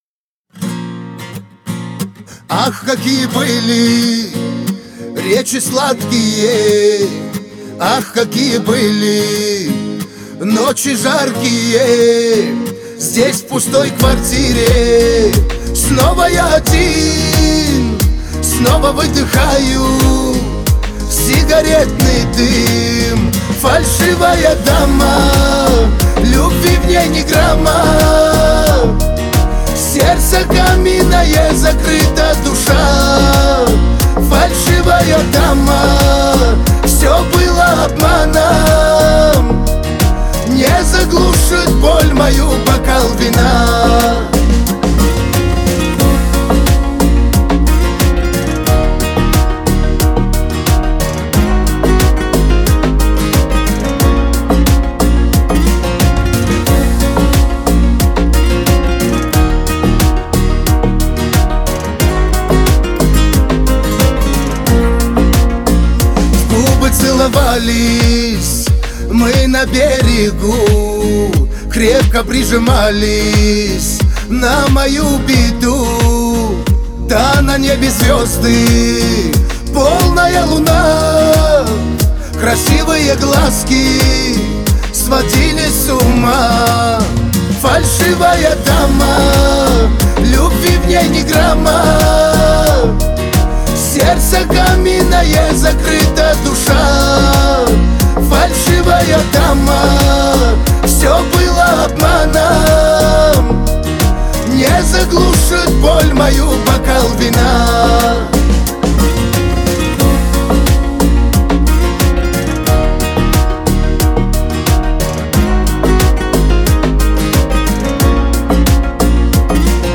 это яркая и запоминающаяся композиция в жанре поп-музыки
глубокий вокал и выразительные мелодии